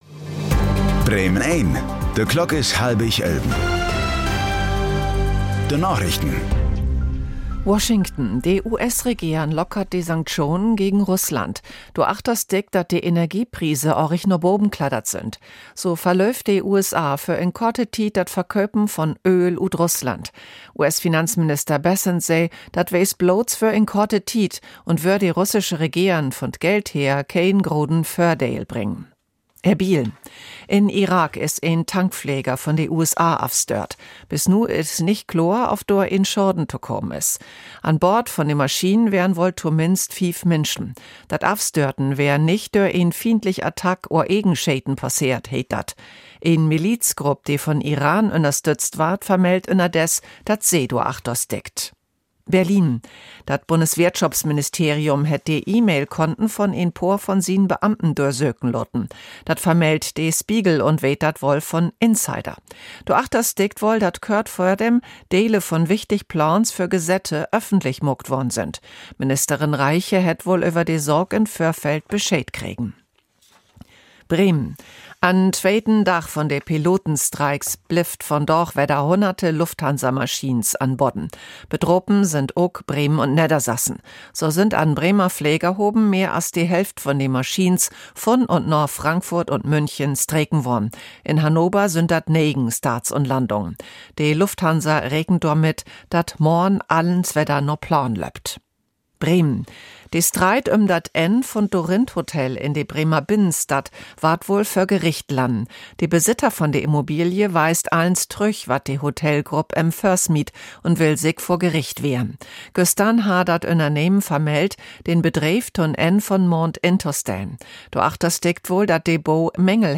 Plattdüütsche Narichten vun'n 13. März 2026